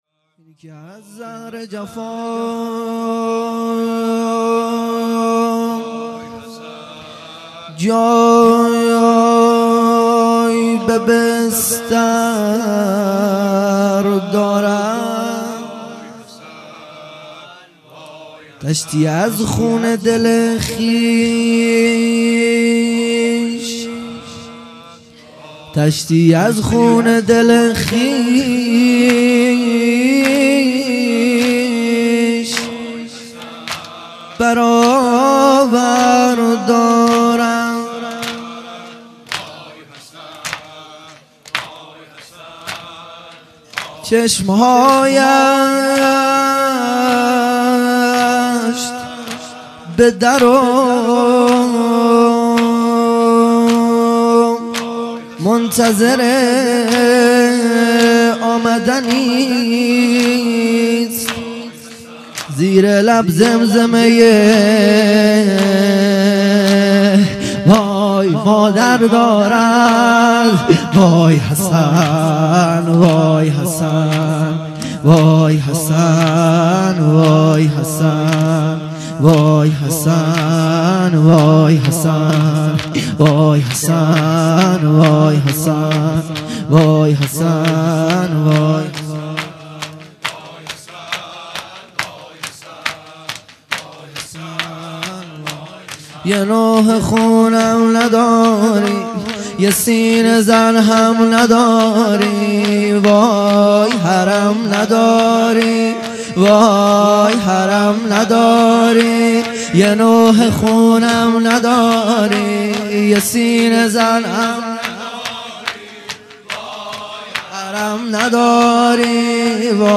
هیئت مکتب الزهرا(س)دارالعباده یزد - شور | یه نوحه خون هم نداری مداح